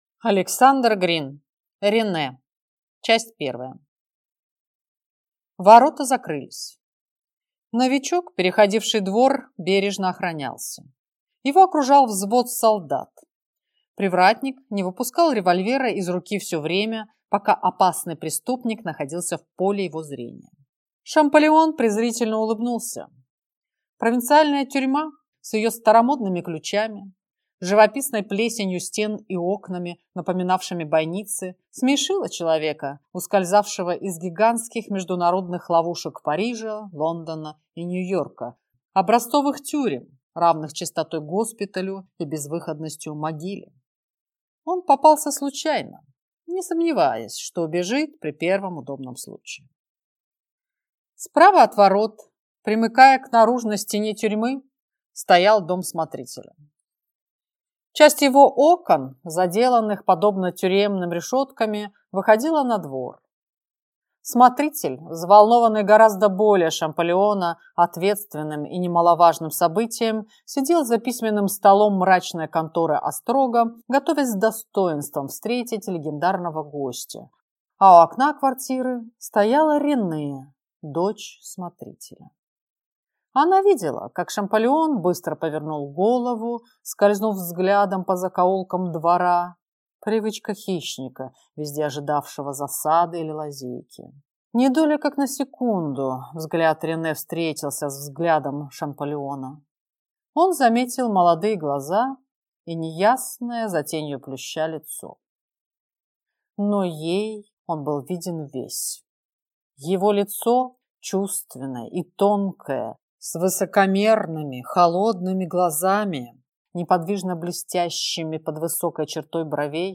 Аудиокнига Рене | Библиотека аудиокниг